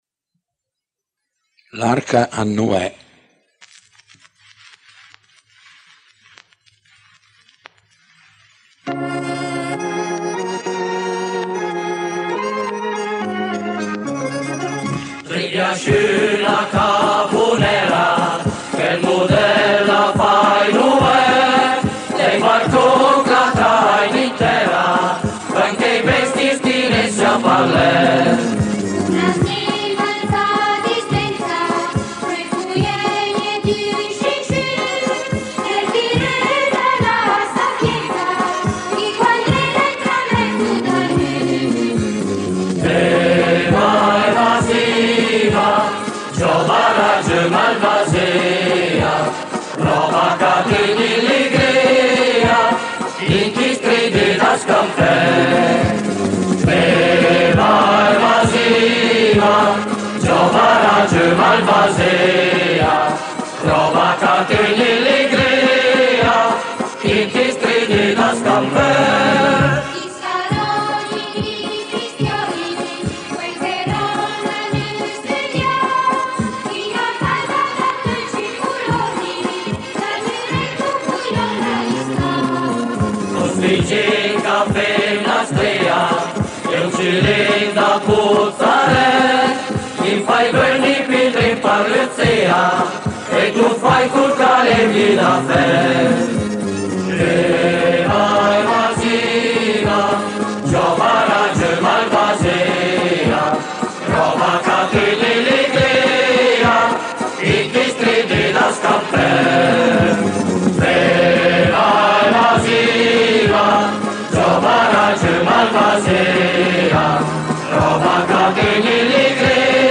canzone popolare
voci Gruppo Folkloristico, chitarra
fisarmonica